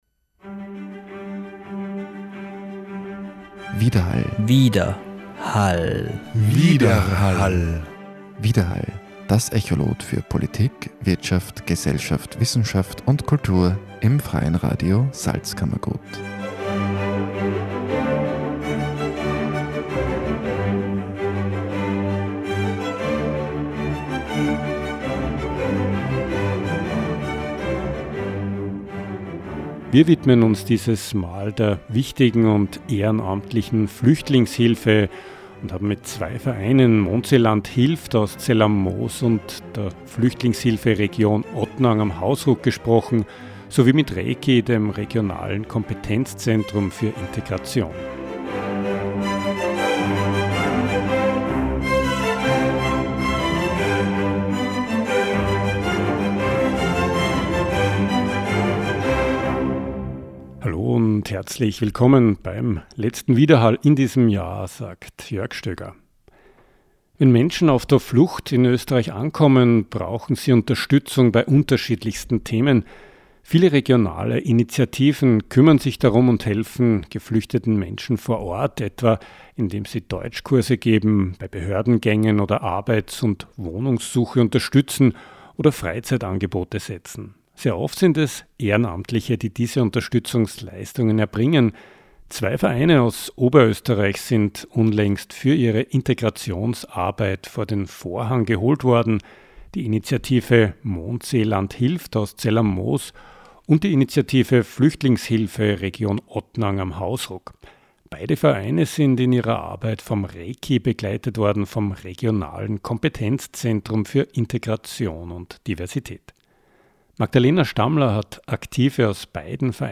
Zum Gespräch haben wir sowohl das ReKI Vöcklabruck, als auch Personen aus den Vereinen getroffen und uns über Integrationsarbeit auf kommunaler Ebene unterhalten.